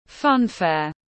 Funfair /ˈfʌn.feər/